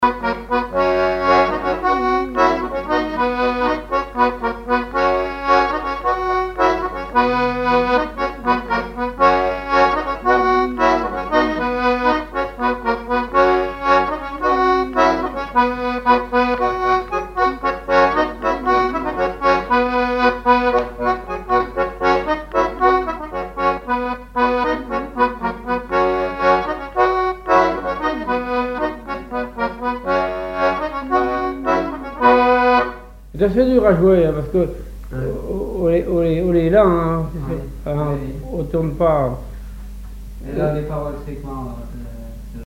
branle : courante, maraîchine
Répertoire sur accordéon diatonique
Pièce musicale inédite